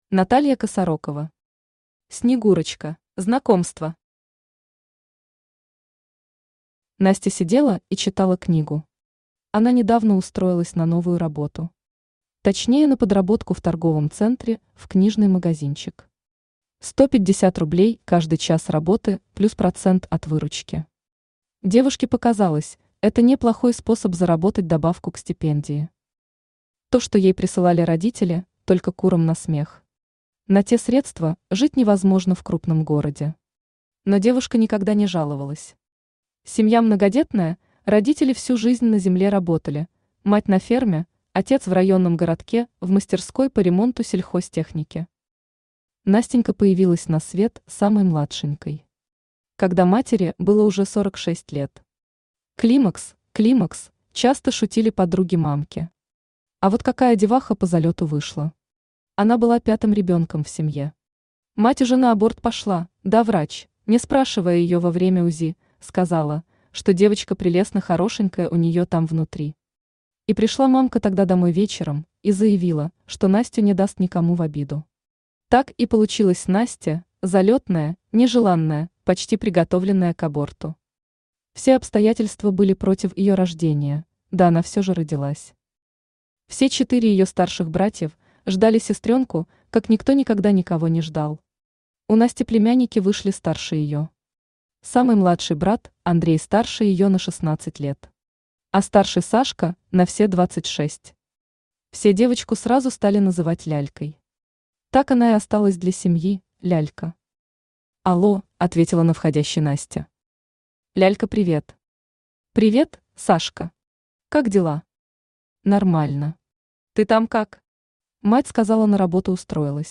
Aудиокнига Снегурочка Автор Наталья Владимировна Косарокова Читает аудиокнигу Авточтец ЛитРес.